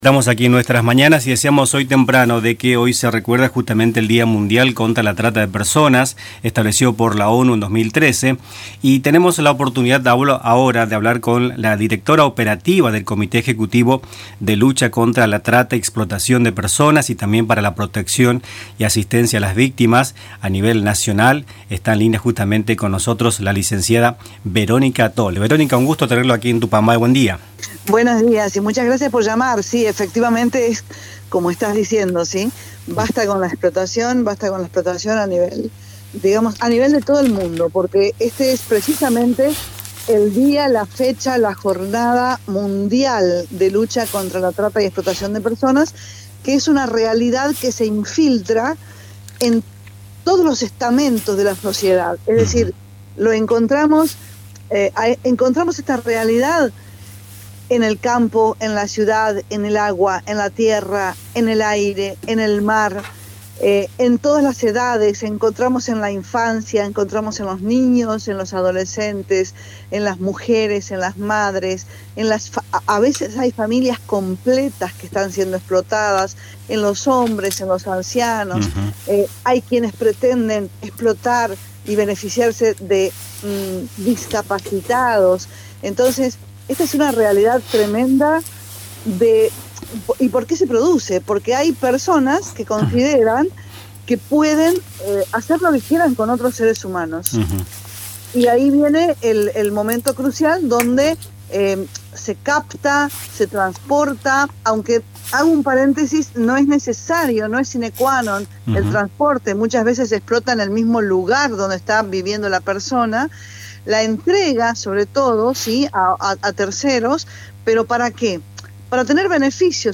En el Día Internacional de la Trata de Personas, en “Nuestras Mañanas”, entrevistamos a la Lic. Verónica Toller, directora operativa del Comité Ejecutivo de Lucha Contra la Trata y Explotación de Personas y para la Protección y Asistencia a las Víctimas, a nivel nacional.